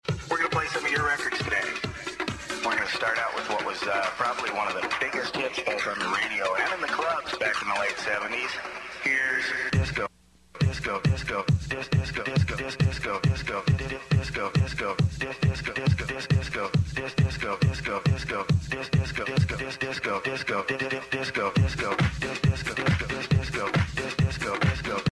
It's classic funky house, and the (spoken) vocals go like this: